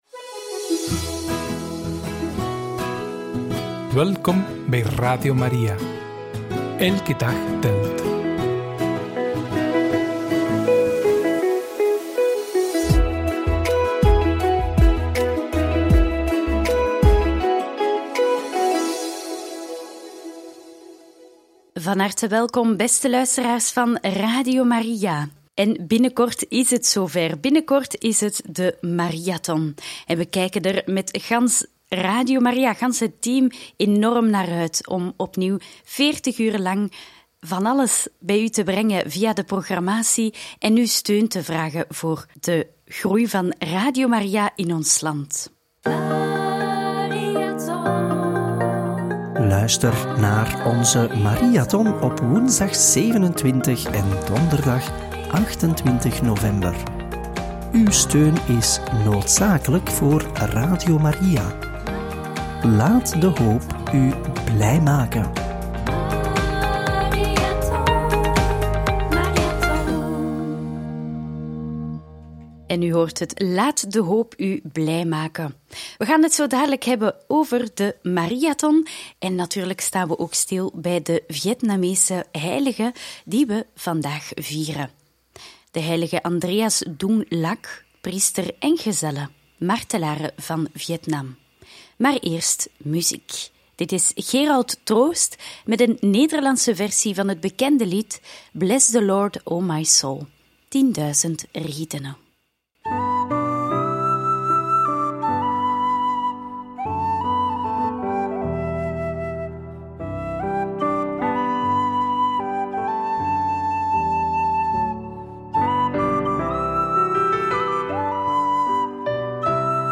Nog wat uitleg over onze Mariathon en homilie van Paus Johannes Paulus II tijdens heiligverklaring van de martelaren van Vietnam – Radio Maria